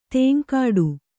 ♪ tēŋkāḍu